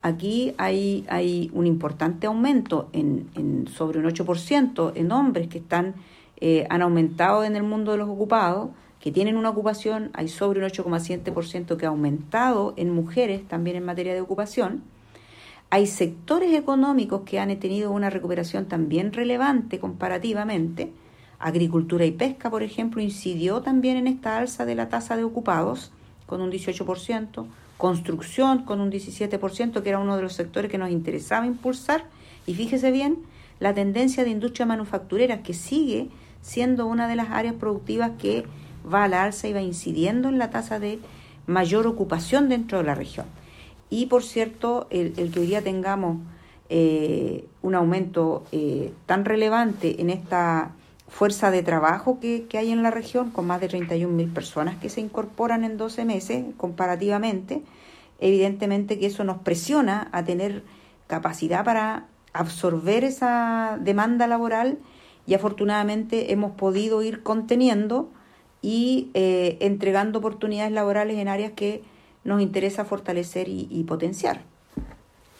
Mientras que la Seremi del Trabajo, Claudia Santander, explicó que
Seremi-del-Trabajo-31-05.mp3